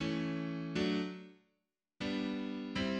"Charleston" rhythm, simple rhythm commonly used in comping.[1]
Charleston_rhythm.mid.mp3